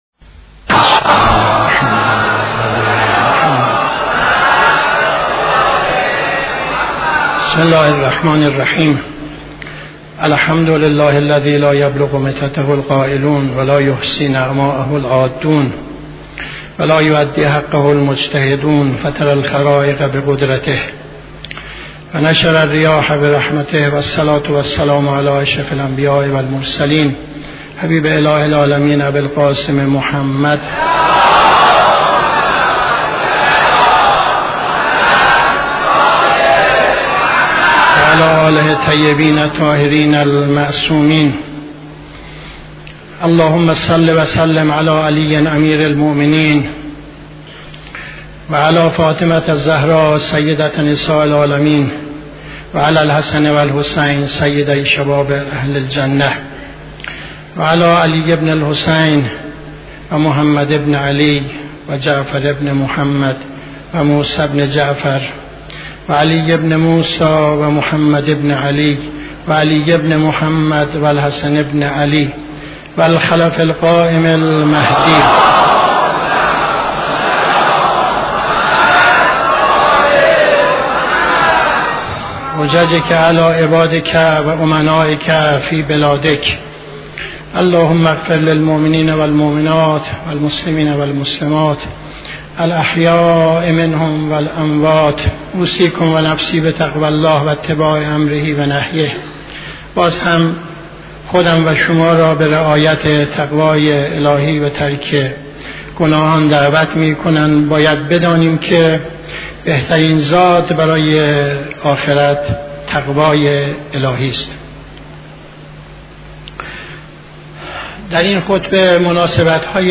خطبه دوم نماز جمعه 03-09-74